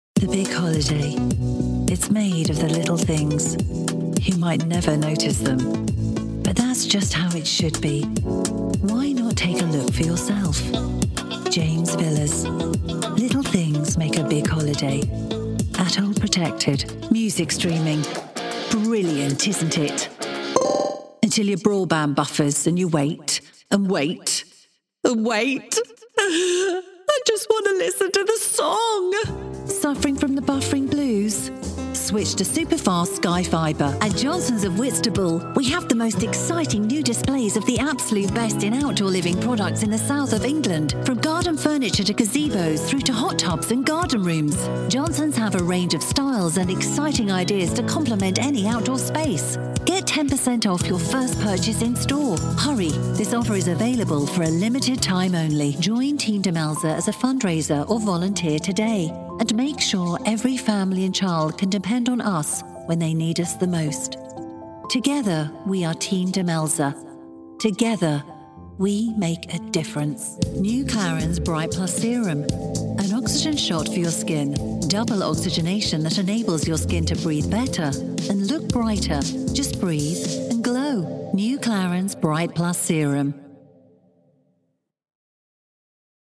With my clear, bright British tones, I bring a touch of class to every project, ensuring your message resonates with listeners everywhere.
Commercial Demo Reel
Words that describe my voice are British, Friendly, Natural.